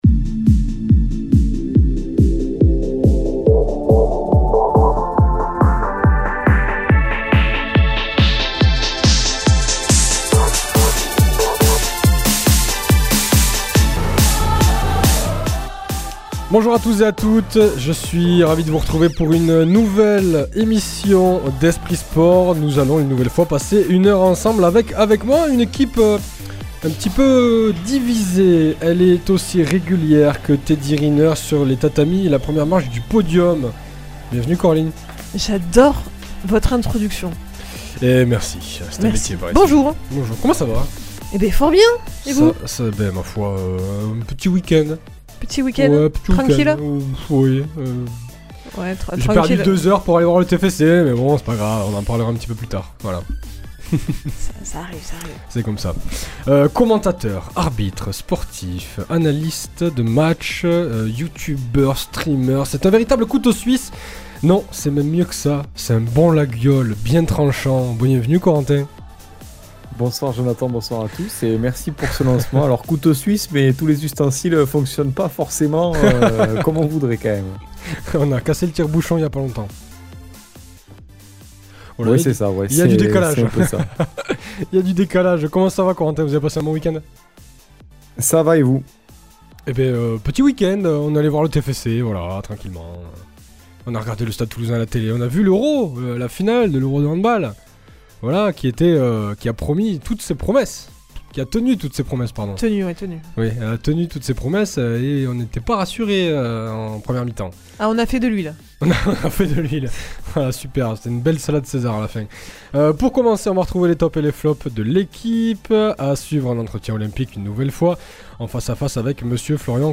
À suivre dans ce nouveau podcast, beaucoup de sports de balles, du basket, du golf, du tennis de table, du foot, du tennis. Un nouvel entretien olympiques